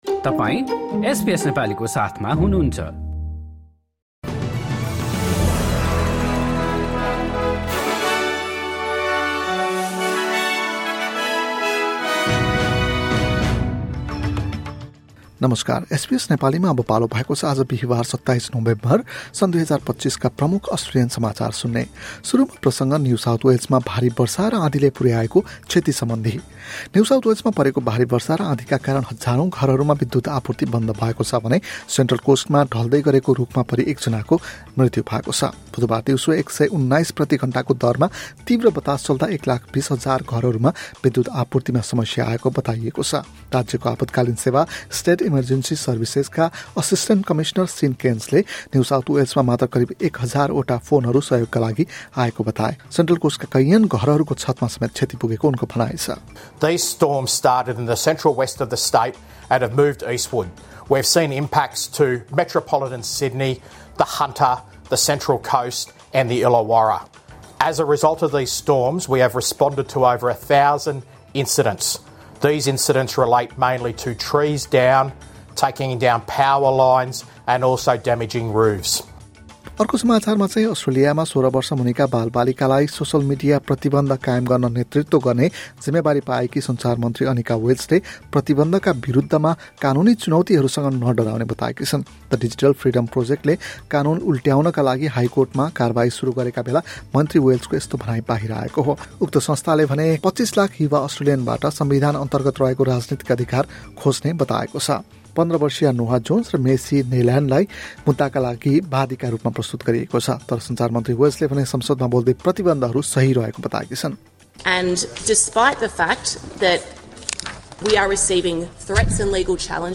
एसबीएस नेपाली प्रमुख अस्ट्रेलियन समाचार: बिहीवार, २७ नोभेम्बर २०२५